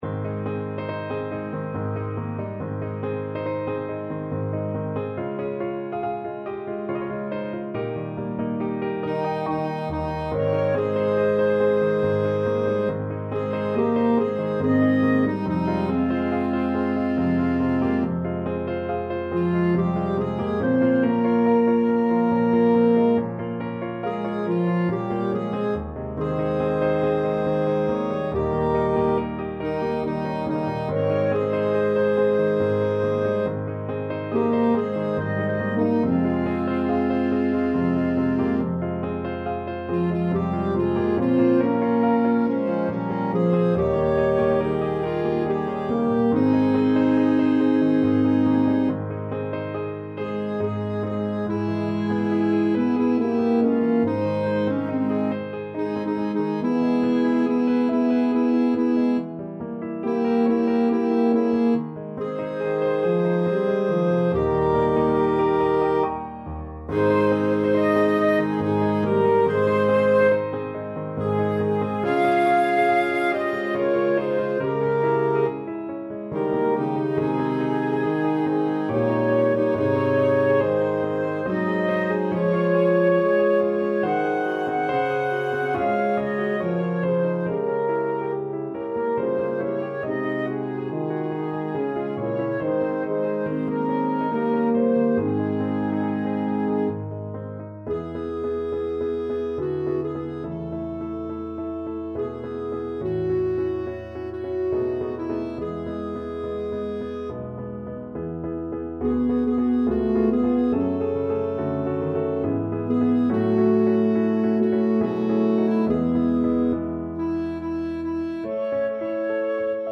SAB mixed choir and piano
世俗音樂